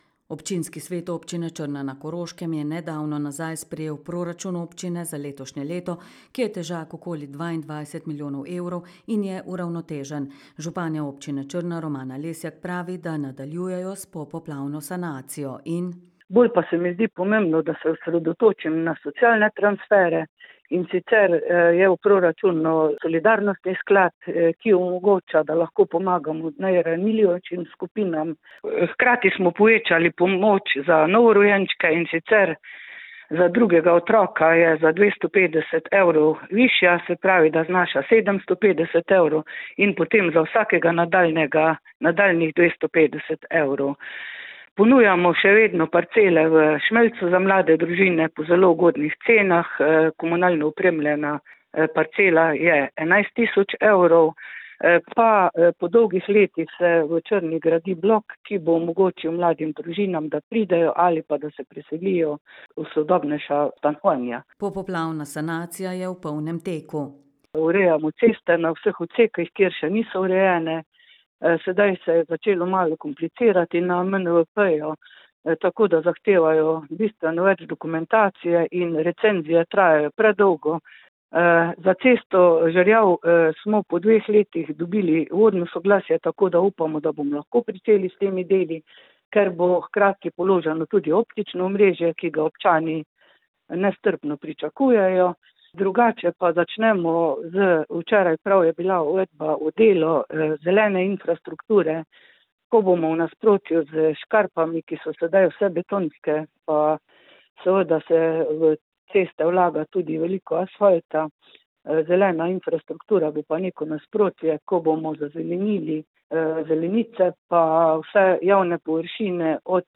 Občinski svet občine Črna na Koroškem je nedavno nazaj sprejel proračun občine ta letošnje leto, ki je težak okoli 22 mio evrov in je uravnotežen. Županja občine Črna, Romana Lesjak pravi, da nadaljujejo s popoplavno sanacijo: